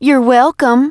piper_kill_03.wav